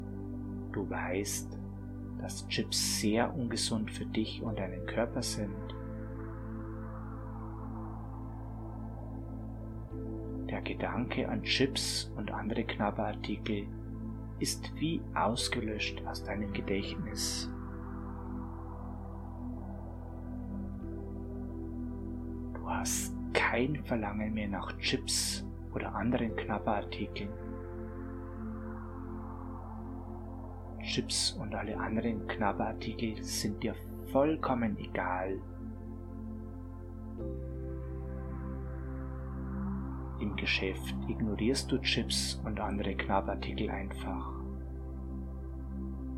Hörprobe: G2009 – Geführte Hypnose „Besser Abnehmen ohne Chips“